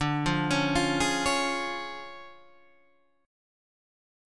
D Minor Major 7th Flat 5th